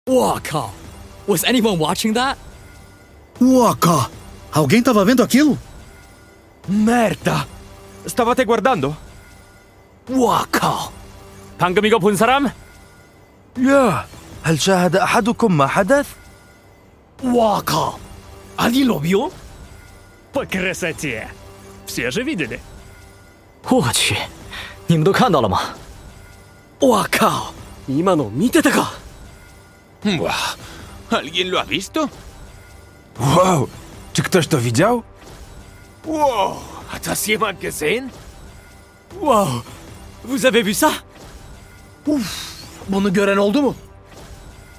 Iso Ace Voice Lines in sound effects free download